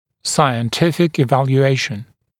[ˌsaɪən’tɪfɪk ɪˌvælju’eɪʃn][ˌсайэн’тифик иˌвэлйу’эйшн]научная оценка